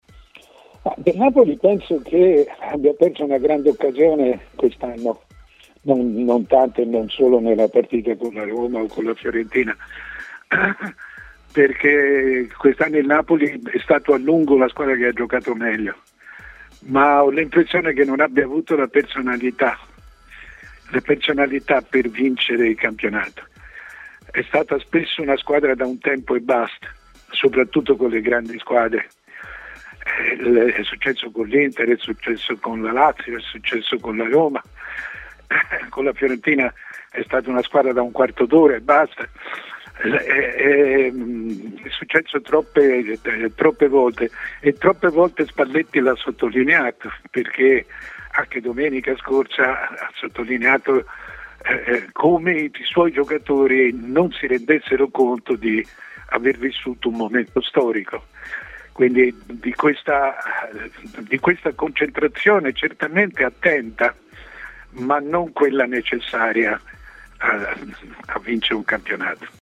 L'opinionista Mario Sconcerti ha parlato del Napoli ai microfoni di Tmw Radio.